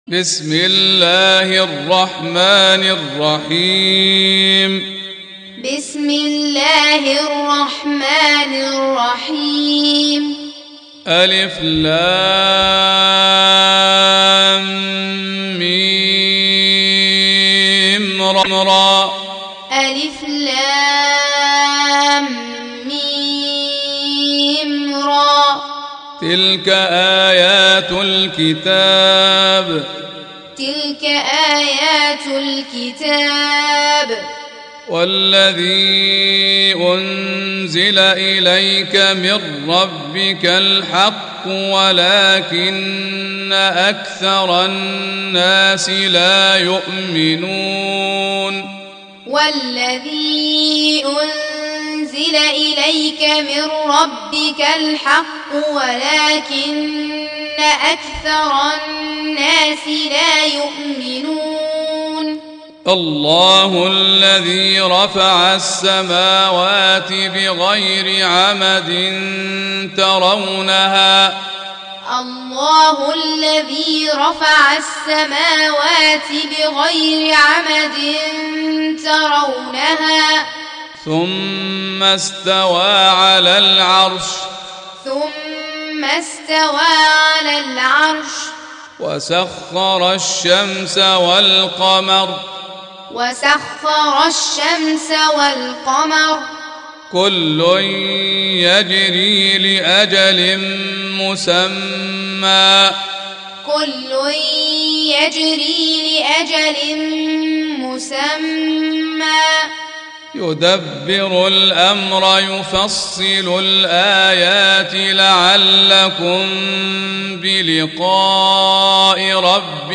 Tutorial Recitation